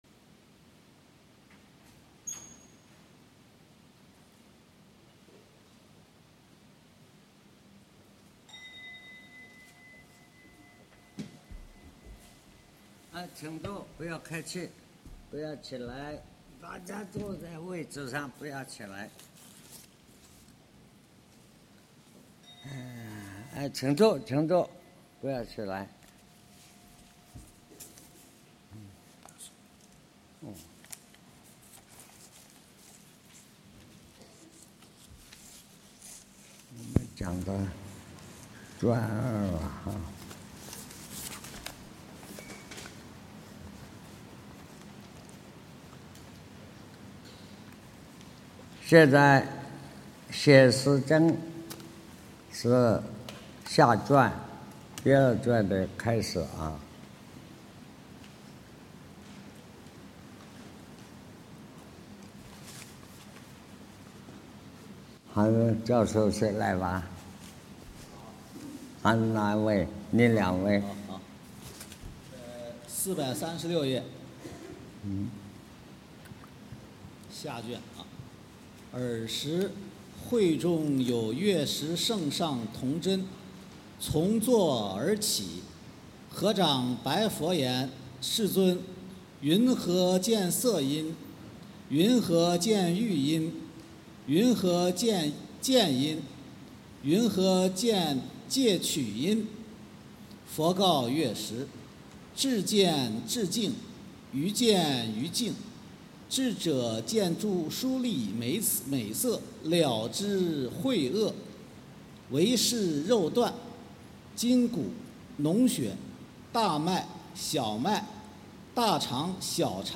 识与见思惑 南怀瑾先生讲大乘显识经(5)